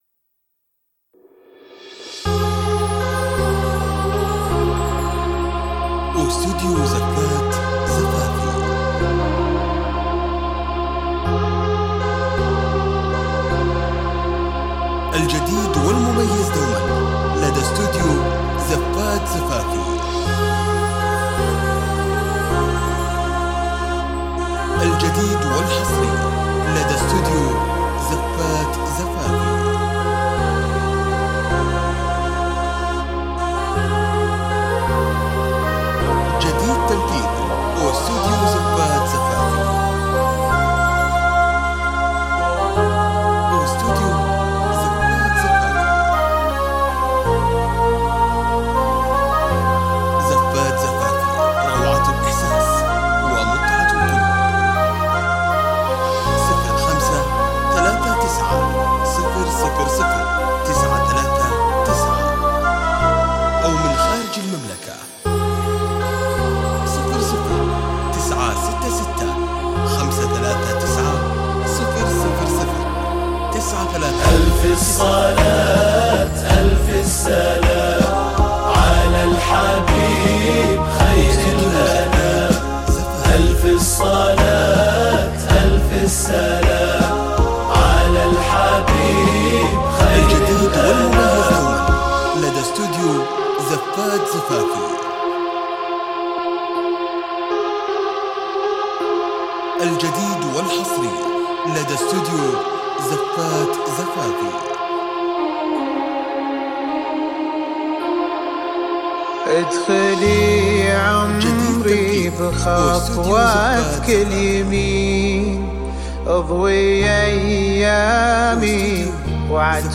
زفات عروس – زفافي